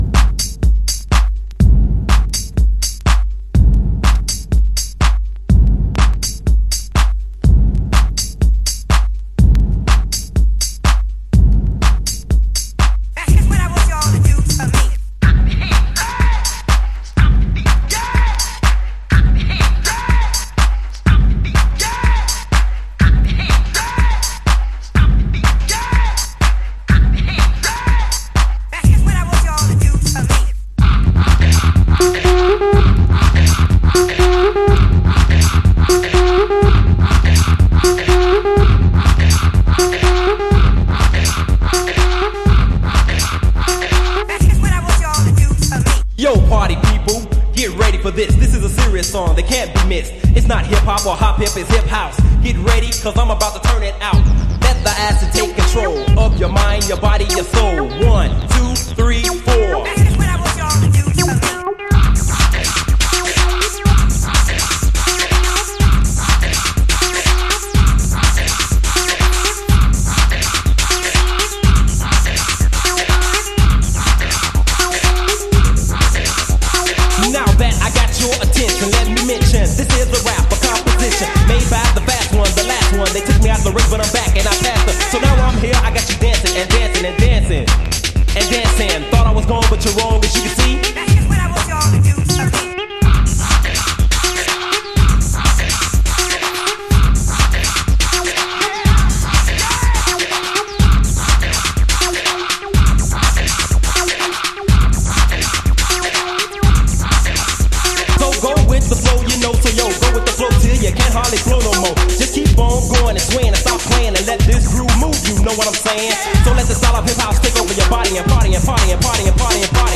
スッカスカのビートが愛らしくてしかたない。